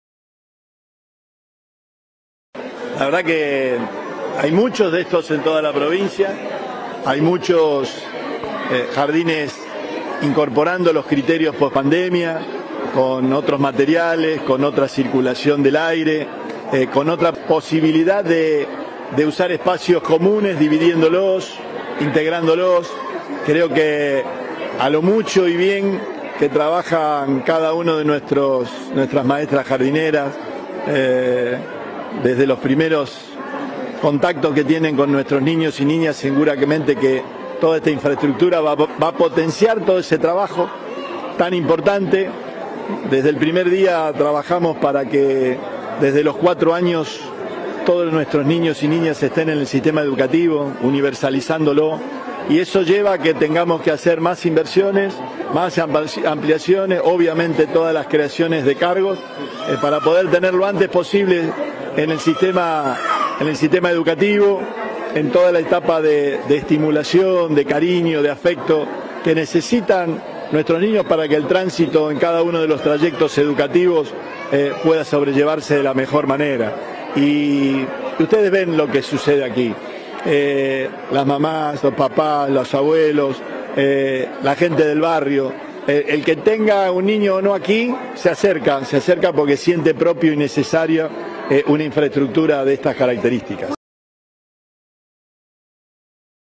Declaraciones Perotti Jardín N° 384